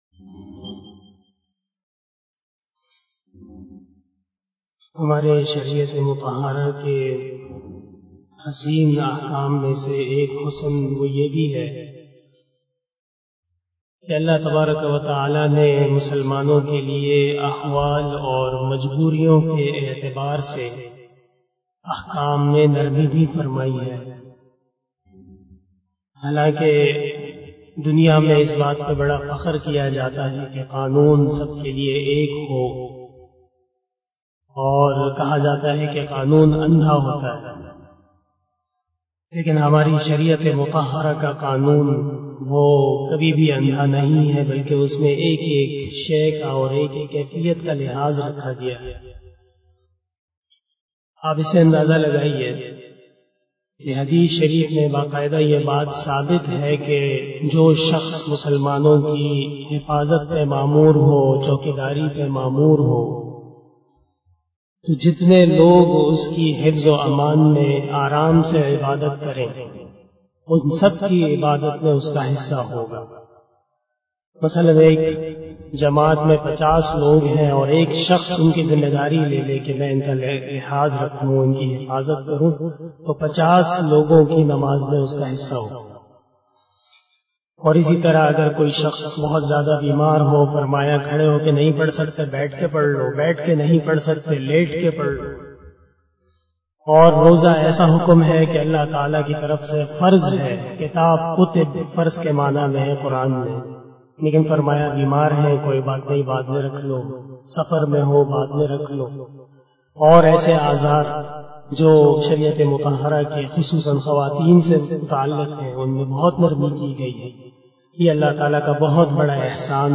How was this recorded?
056 After Asar Namaz Bayan 12 Setember 2021 (04 Safar 1443HJ) Sunday